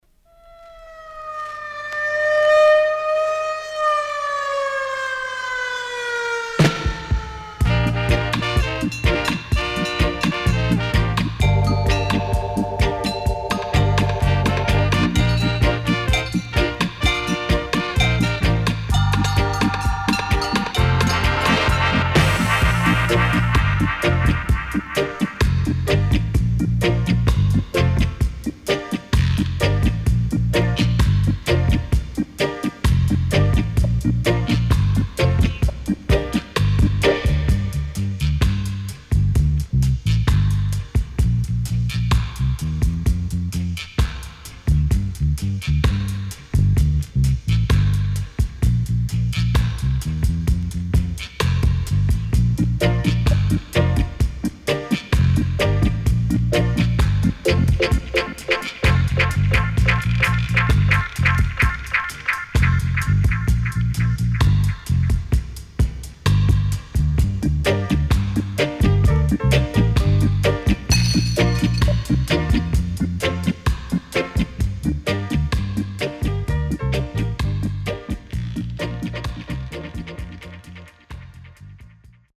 HOME > Back Order [VINTAGE DISCO45]  >  INST 70's
SIDE A:少しノイズ入りますが良好です。